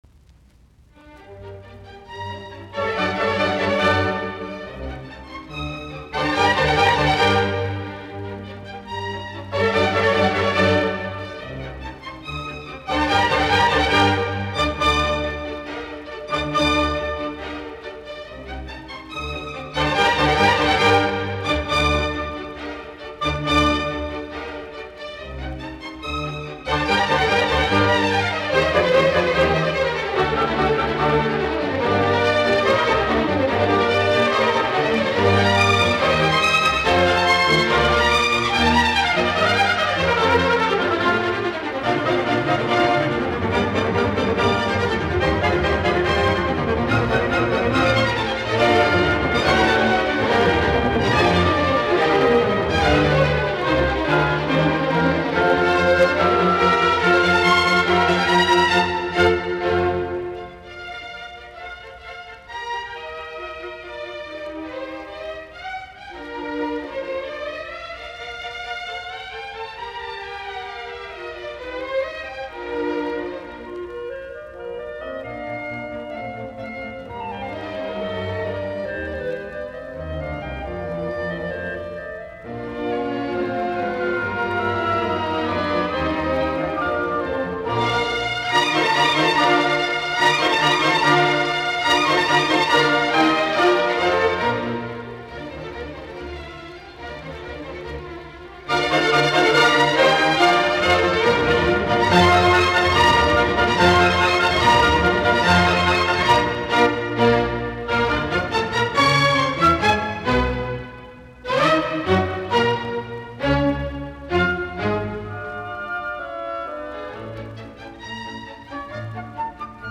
Symphony no. 40 : in g minor, K. 550
Sinfoniat, nro 40, KV550, g-molli
Soitinnus: ork.